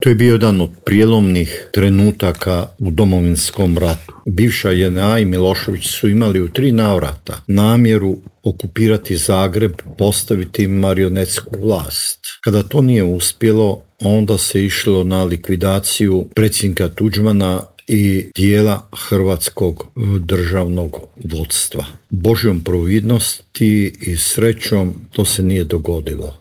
Cilj je bio eliminirati predsjednika Franju Tuđmana, a tog se dana u Intervjuu tjedna Media servisa prisjetio bivši ministar vanjskih poslova Mate Granić.